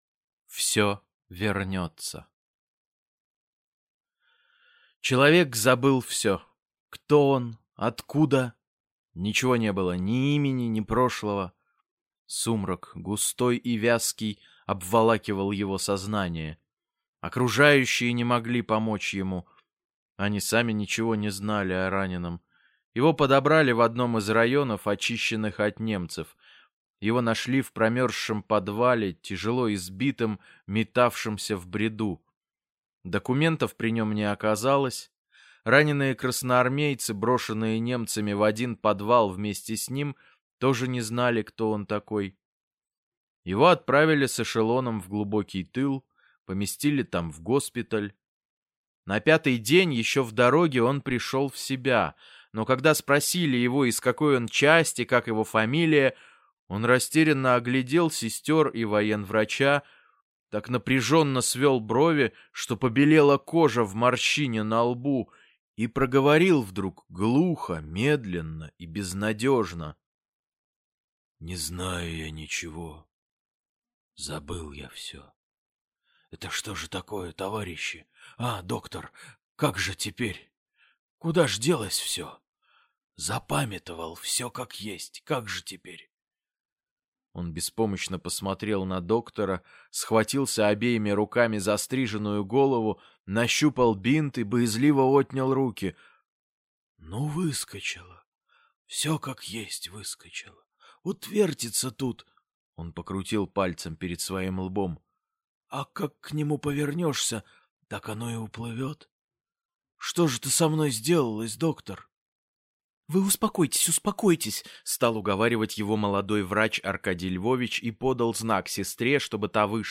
Все вернется - аудио рассказ Льва Кассиля - слушать онлайн